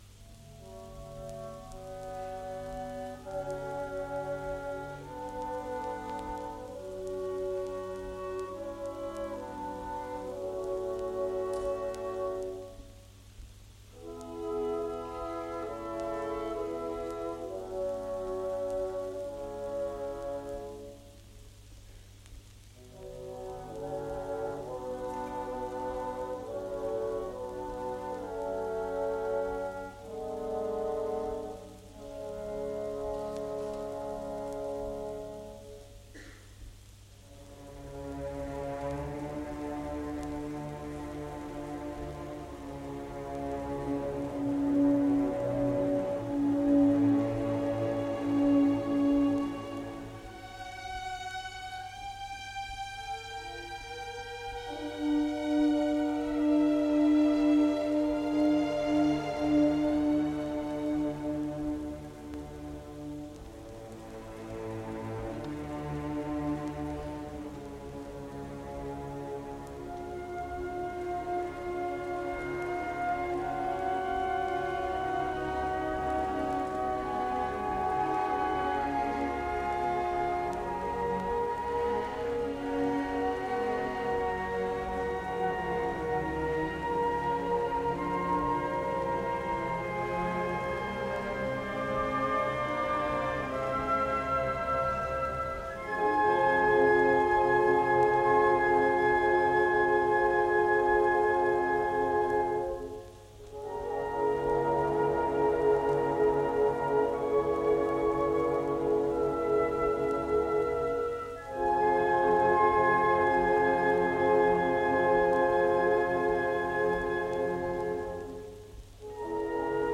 CMEA 1983 honors concert, digital audio rescues